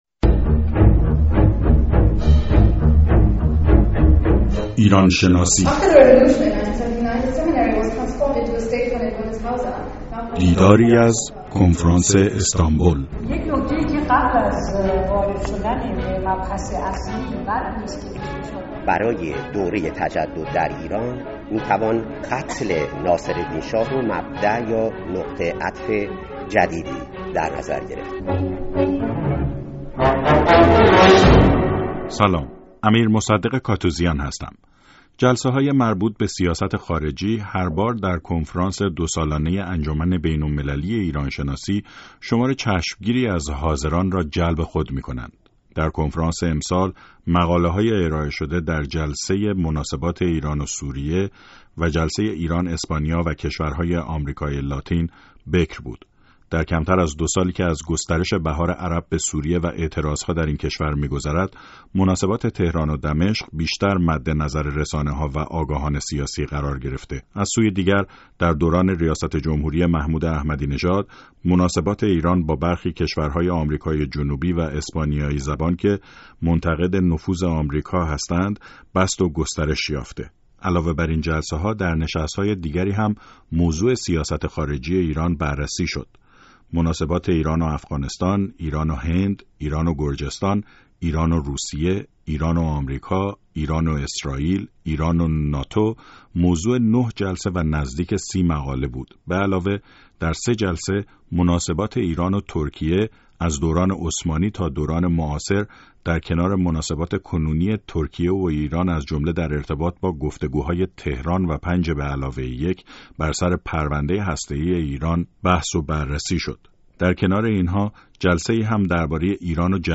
ایران شناسی: دیدار از کنفرانس استانبول (۵) گفت‌وگو با خوان کول مورخ و تحلیلگر آمریکایی